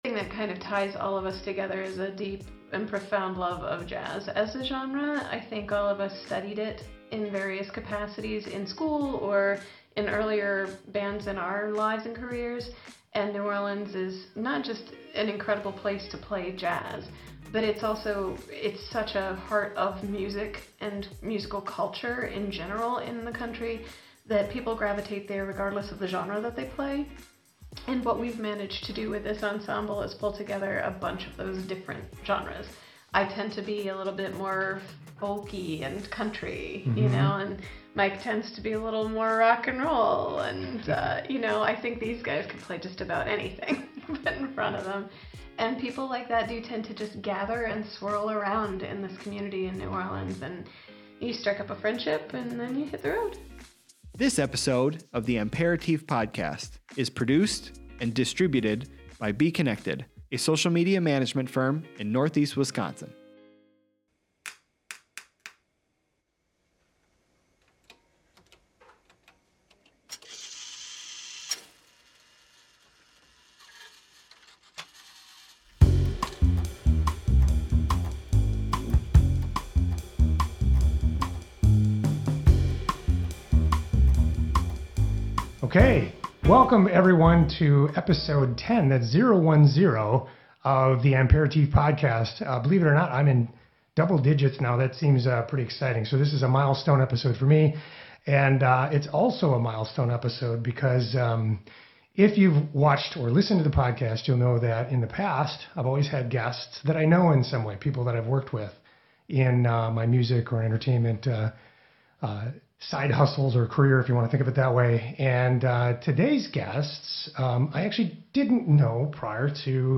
I speak with the quartet about coming up to the area for Appleton’s Mile of […]